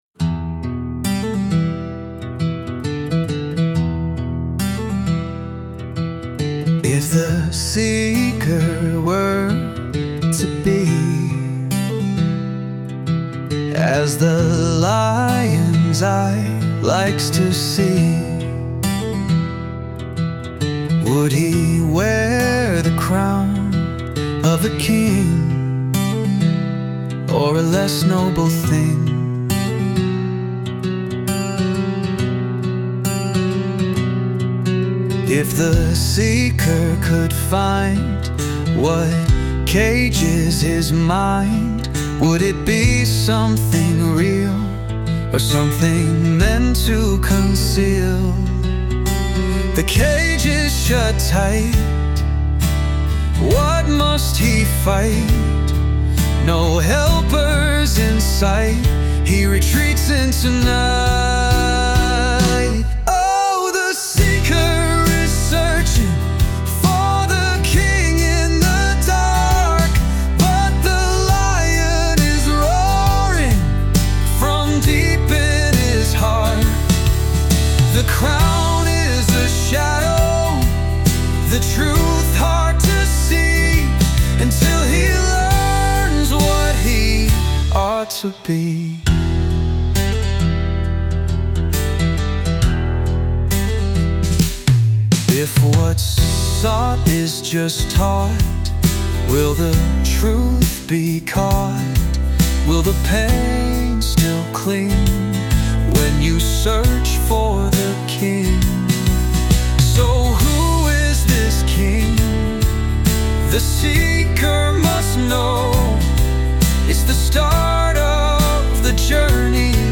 This began as a poem I published in my blog. I have no musical skills, so I worked with AI to produce the music.